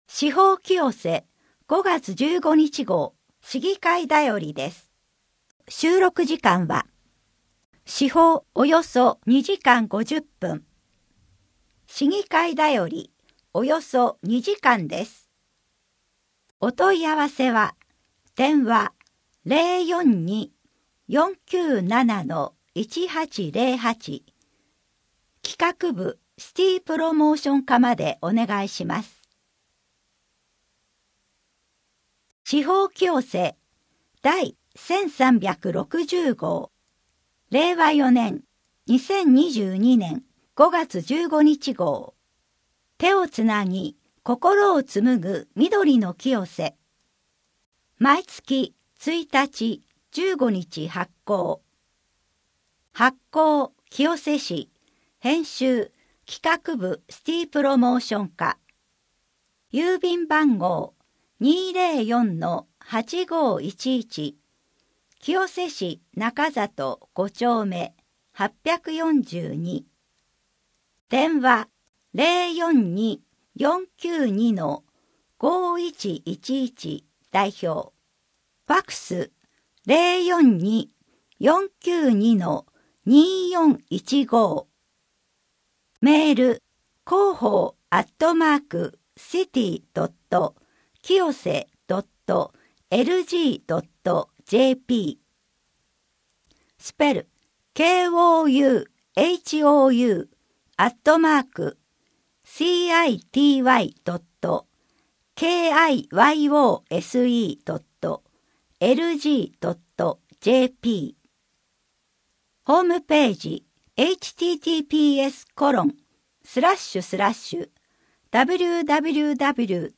医療に関するお役立ち情報（1） 上手な医者のかかり方～かかりつけ医をもちましょう～ まちかどニュース “柳瀬川の風物詩”マルタウグイの産卵 郷土博物館のイベント 令和4年度ミュージアム・シアター2 図書館のお知らせ 特別整理休館 について 清瀬けやきホールの催し物 コミュニティプラザひまわりの催し物 多摩六都科学館の催し物 人口と世帯 令和4年5月15日号8面 （PDF 1.1MB） 声の広報 声の広報は清瀬市公共刊行物音訳機関が制作しています。